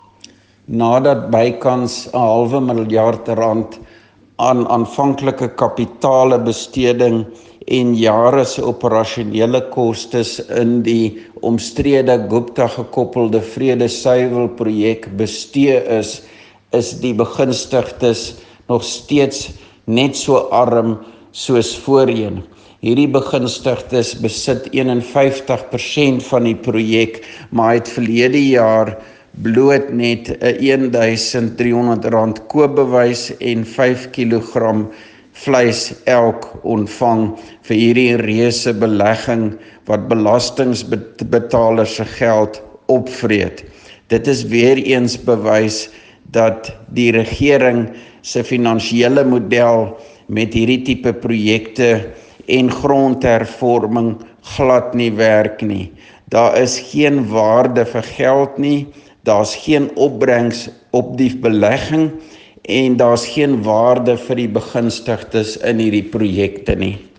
Afrikaans soundbites by Roy Jankielsohn MPL and